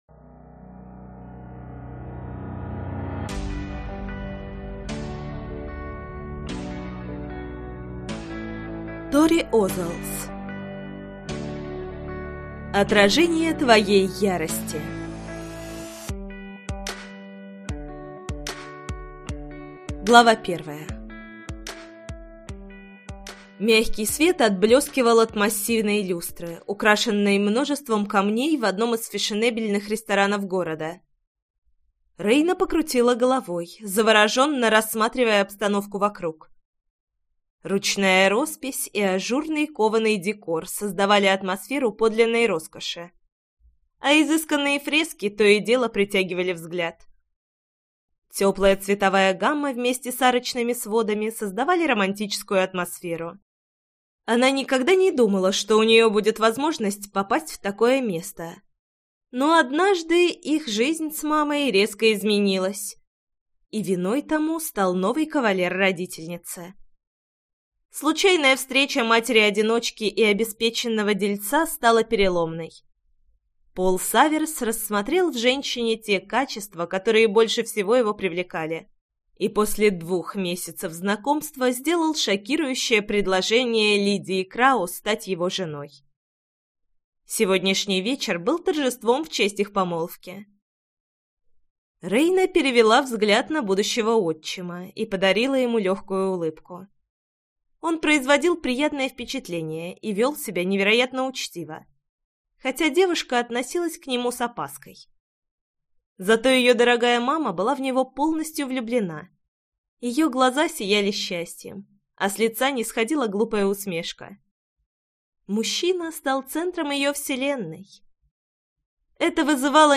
Аудиокнига Отражение твоей ярости - купить, скачать и слушать онлайн | КнигоПоиск